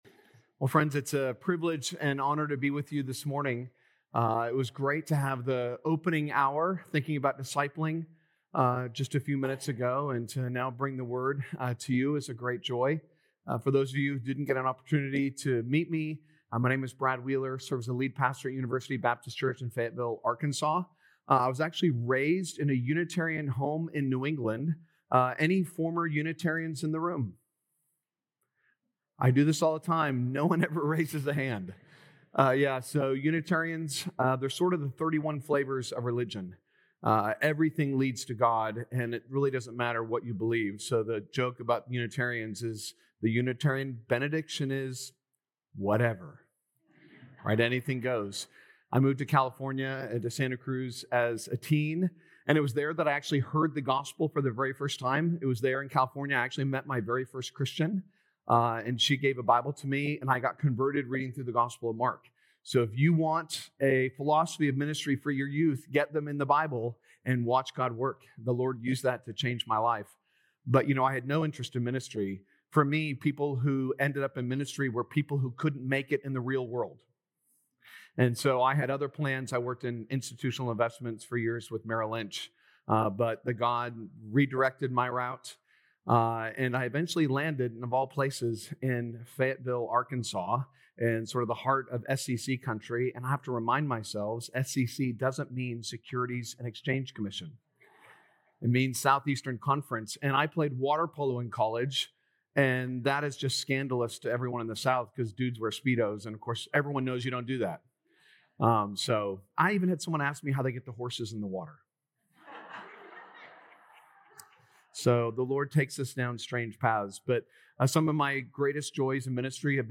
Mar 15th Sermon